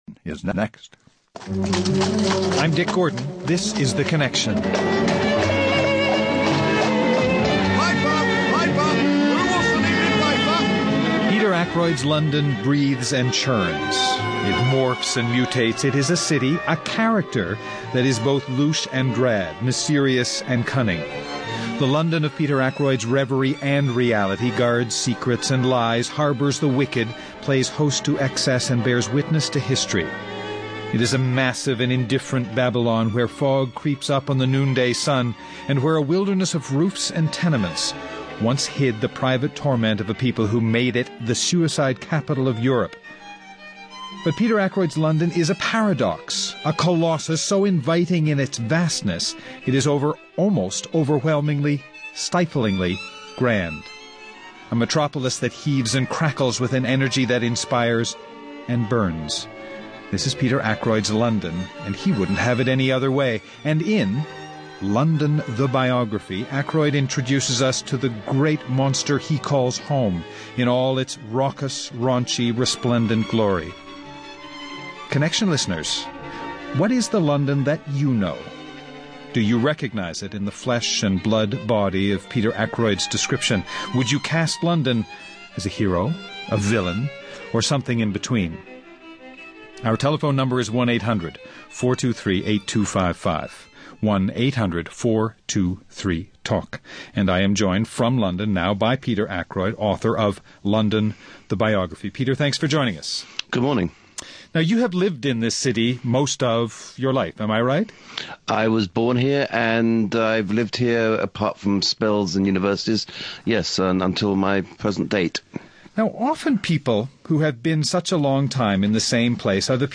Guests: Peter Ackroyd, author, London: The Biography.